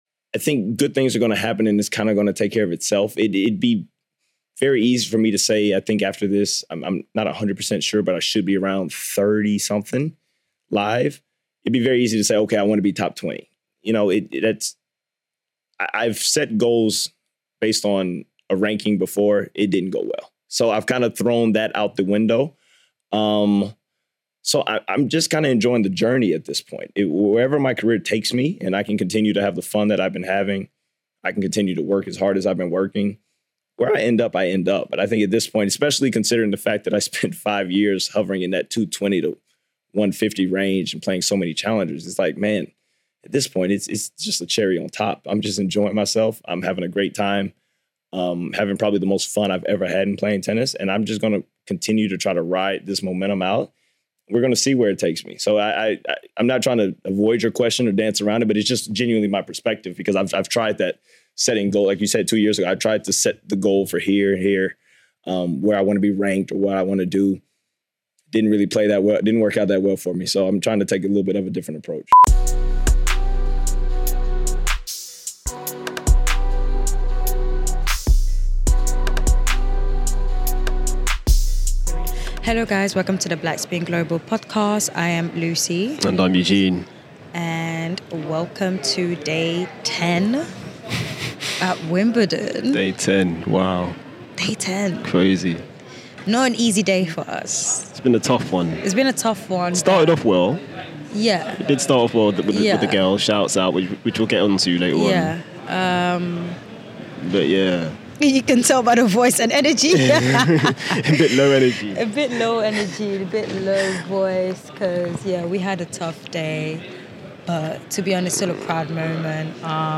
Interview snippets from Eubanks and Keys included.